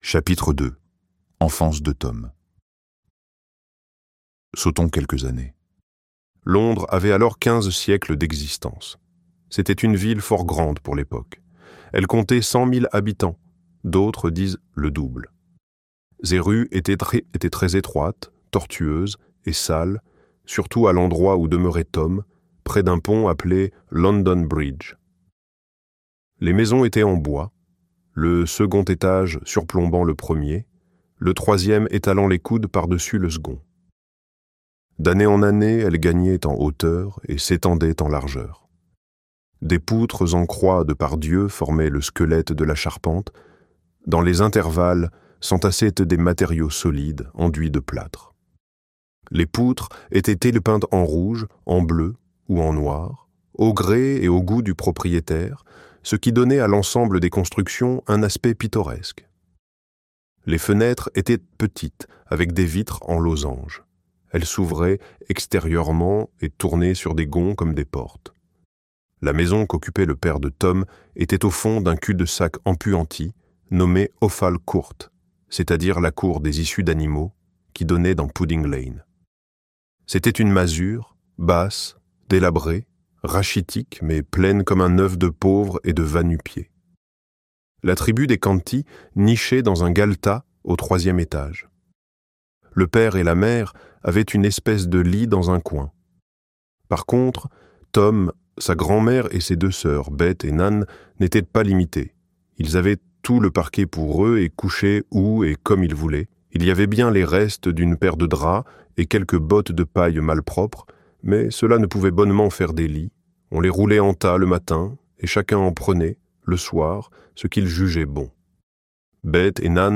Le Prince et le Pauvre - Livre Audio
Extrait gratuit